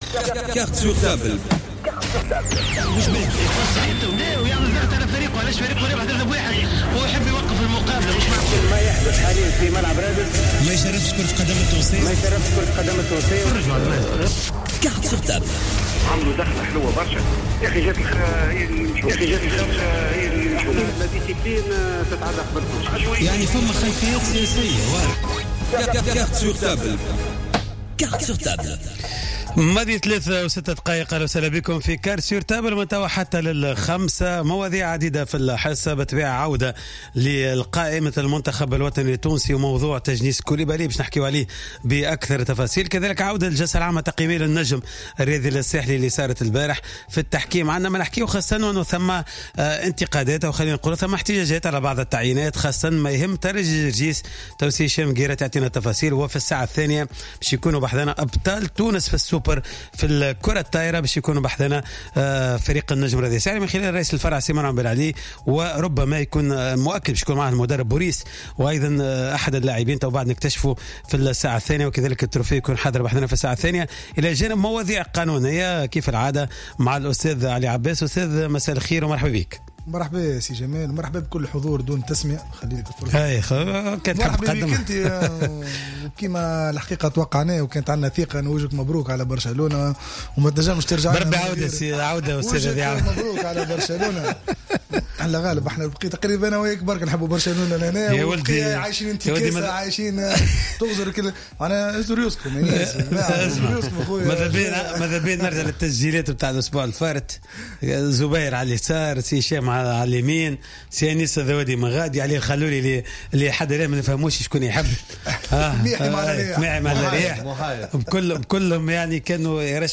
كما تدخل خلال الحصة هاتفيا